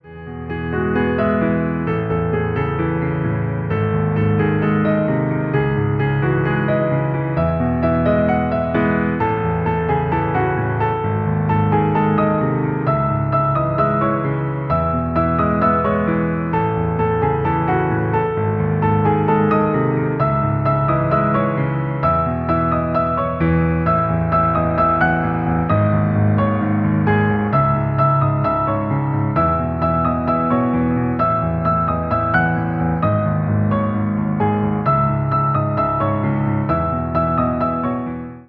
Поп Музыка
кавер # без слов